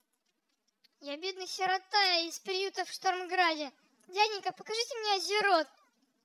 Варианты изменения голоса вы можете видеть на скриншоте.
Записал я звук в WAV для быстроты стандартными средствами Windows.
Orphan
orphan.wav